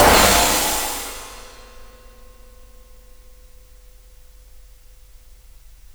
Index of /4 DRUM N BASS:JUNGLE BEATS/KIT SAMPLES/DRUM N BASS KIT 1
RAIN RIDE CRASHED.wav